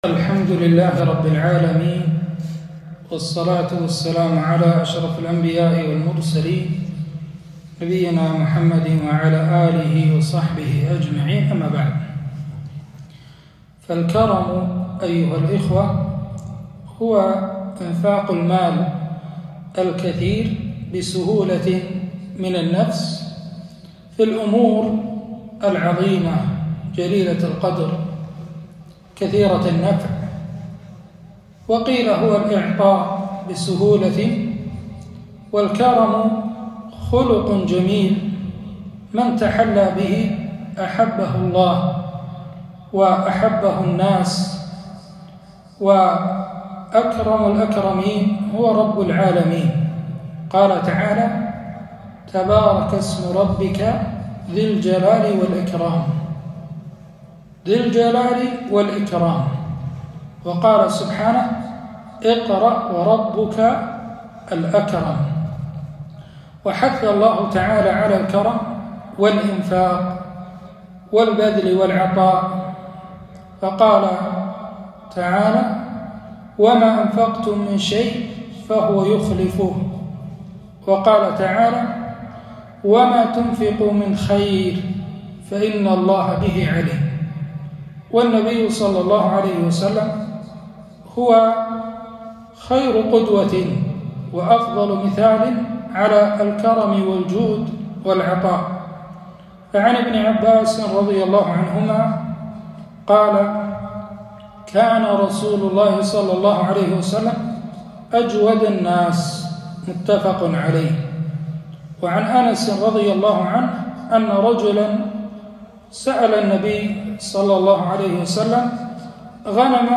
كلمة - الكرم من كمال الإيمان